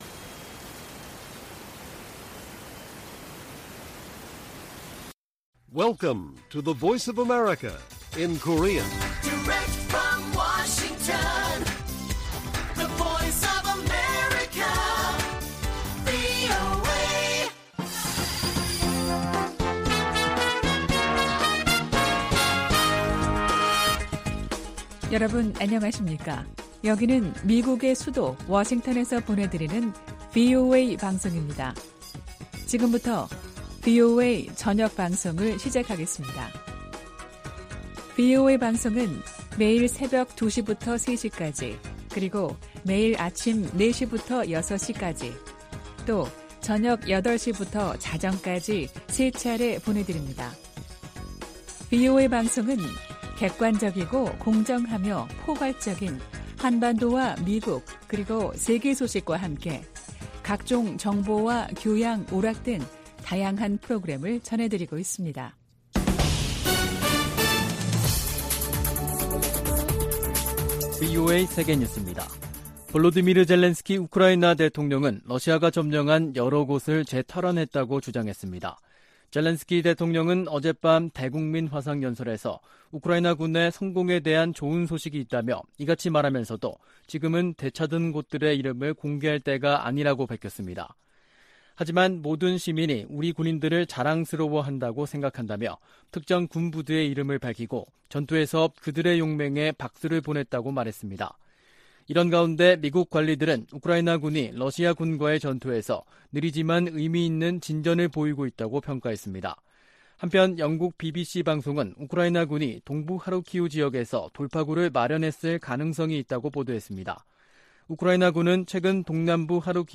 VOA 한국어 간판 뉴스 프로그램 '뉴스 투데이', 2022년 9월 8일 1부 방송입니다. 카멀라 해리스 미국 부통령이 오는 25일부터 29일까지 일본과 한국을 방문할 계획이라고 백악관이 밝혔습니다. 한국 외교부와 국방부는 제3차 미한 외교·국방 2+2 확장억제전략협의체(EDSCG) 회의가 오는 16일 워싱턴에서 열린다고 밝혔습니다. 한국 정부가 북한에 이산가족 문제 해결을 위한 당국간 회담을 공식 제안했습니다.